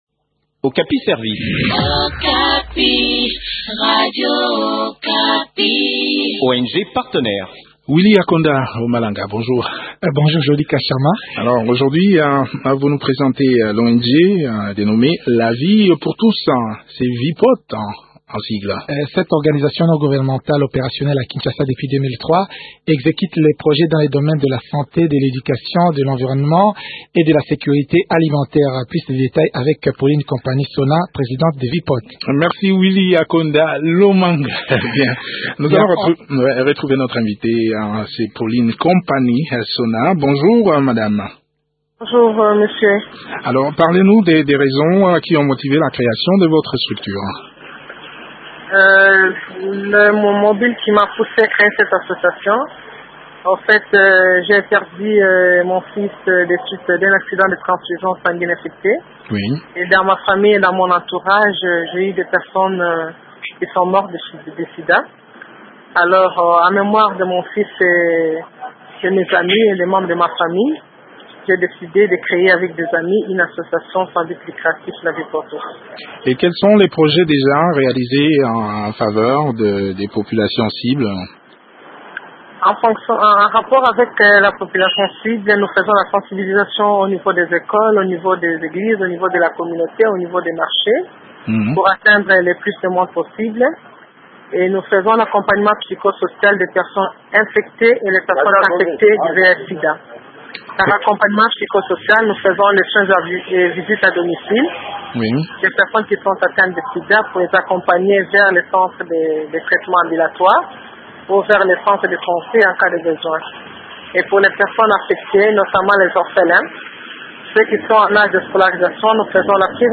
Le point des activités de cette structure dans cet entretien